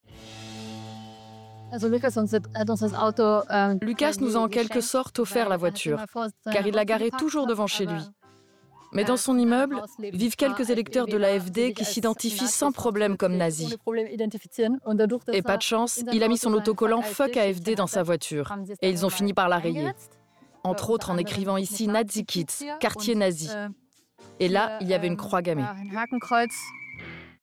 Voix off
Autonome pour enregistrer, chant ou voix, je possède du materiel professionnel (Neumann et Sennheiser)